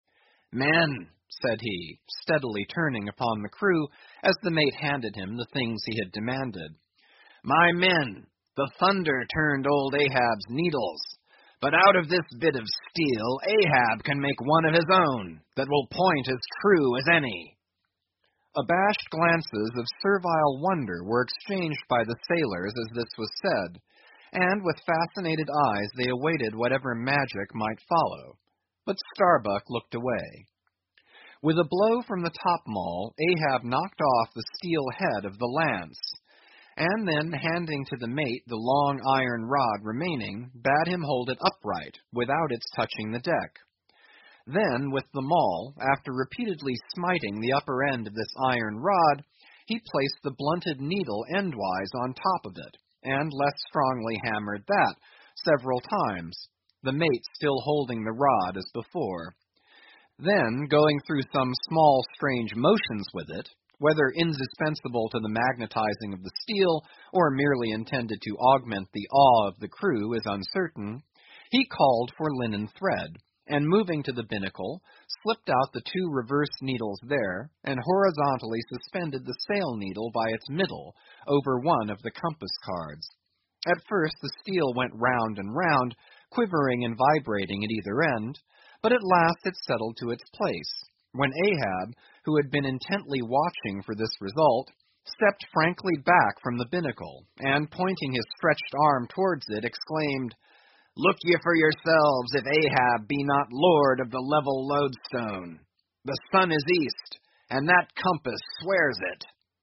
英语听书《白鲸记》第962期 听力文件下载—在线英语听力室